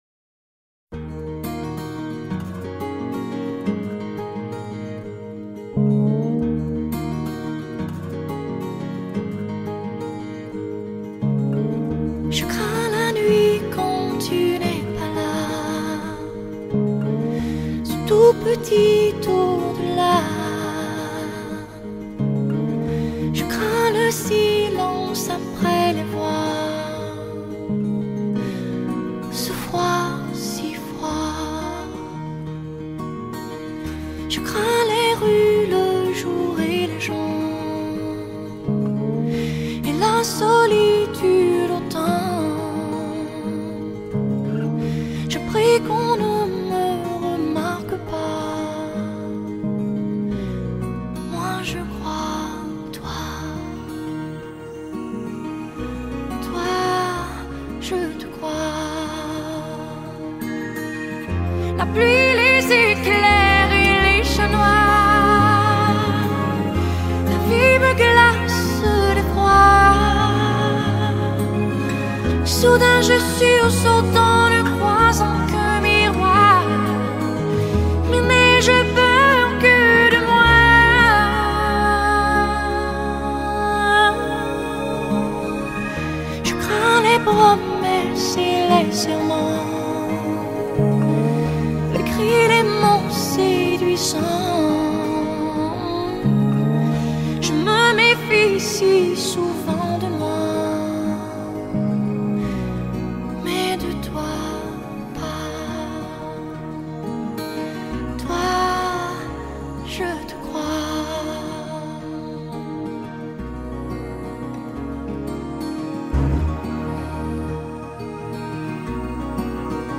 Pop, Chanson